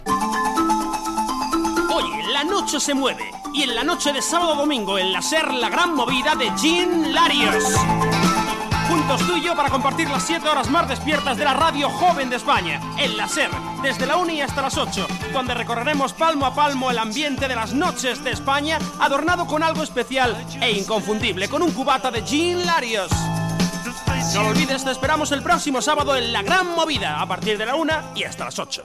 Dues promocions del programa